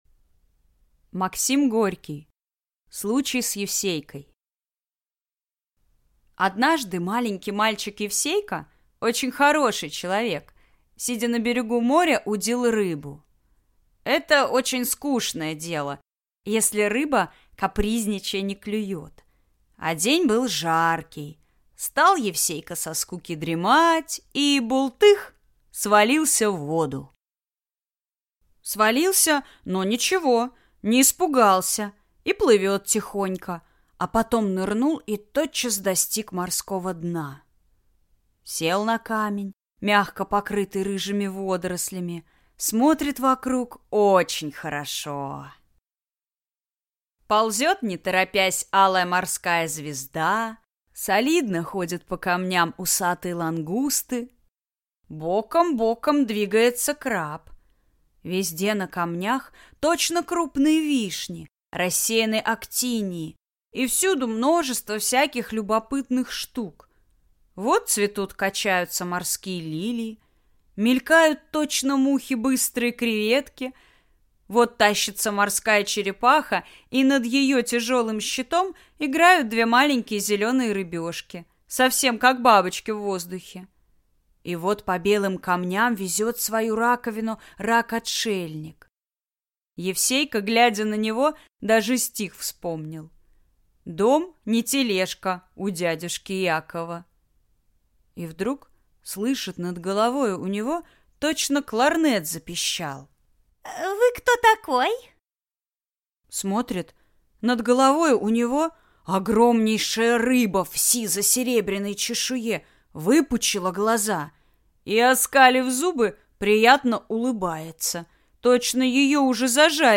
Аудиокнига Случай с Евсейкой | Библиотека аудиокниг